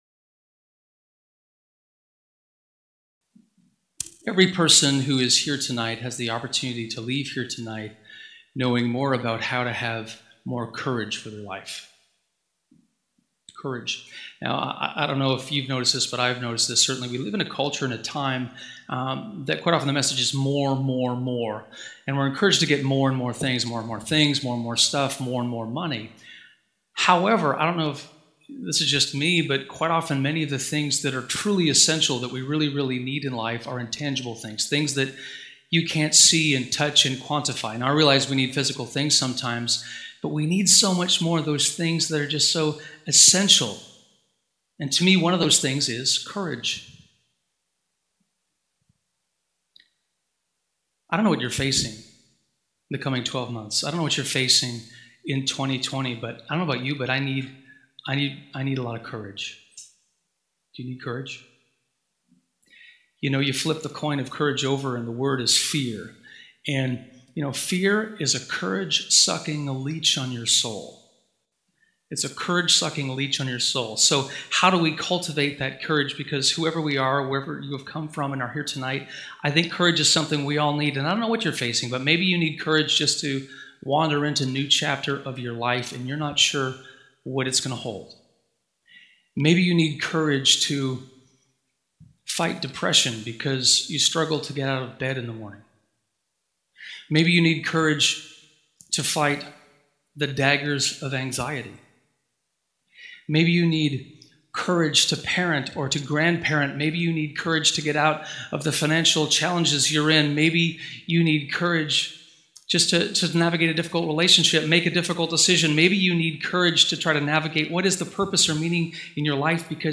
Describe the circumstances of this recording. What are you facing in the next year? This Christmas Eve message explains 'the courage equation' and four ways to draw closer to God in the pursuit of courage.